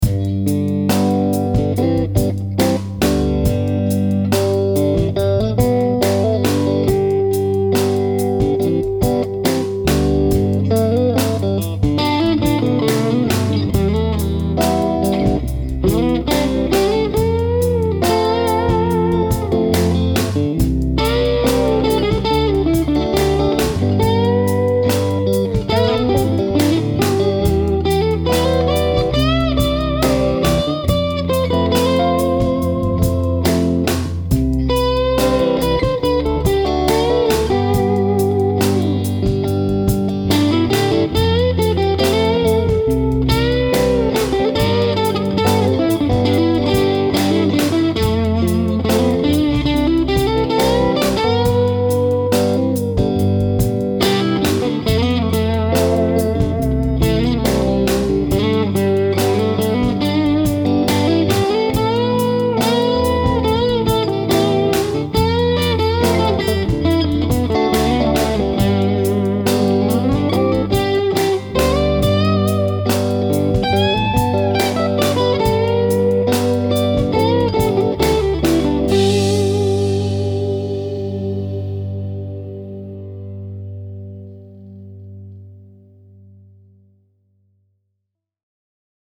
This adds yet another pickup to the bridge that has all sorts of gain; very humbucker-like, but retaining the single-coil characteristics – there’s just more of it.
Finally, here’s the quick song that I recorded this morning that has the rhythm part in fully magnetic mode in the 4th switch position (neck/middle). The lead is played in the first position with the Ctrl-X system:
Both clips were recorded using my Aracom PLX18 BB Trem, a fantastic 18-Watt Plexi clone.